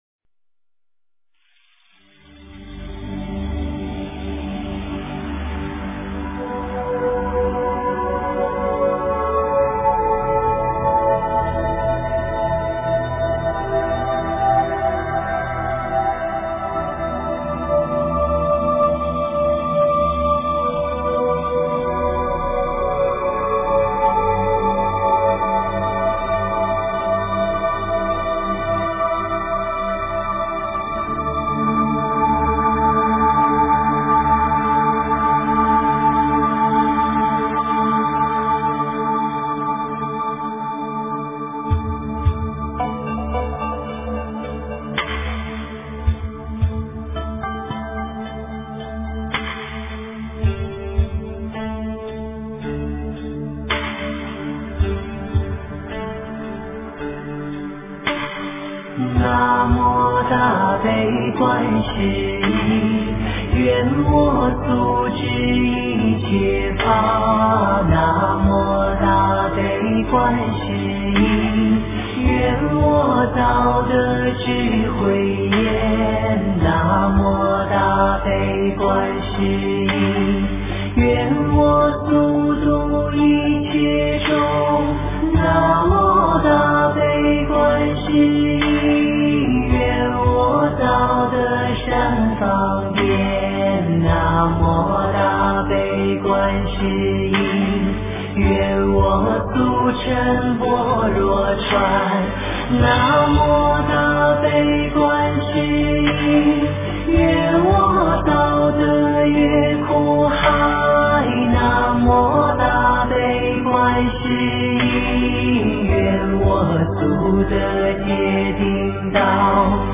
佛音 诵经 佛教音乐 返回列表 上一篇： 三宝歌 下一篇： 心经 相关文章 青空--推荐 青空--推荐...